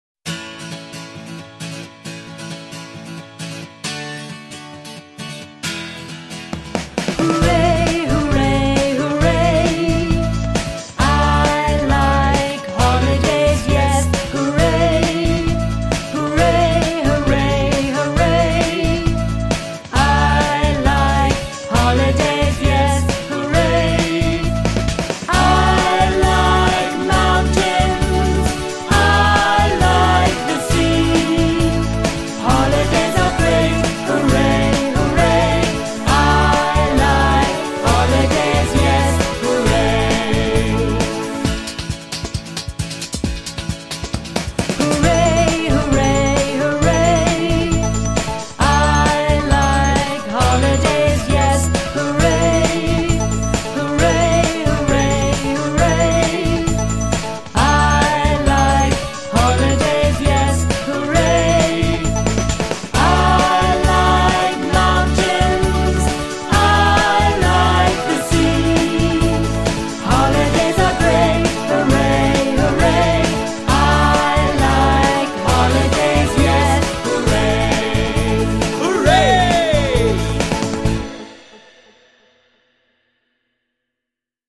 Песенка для изучения английского языка для маленьких детей.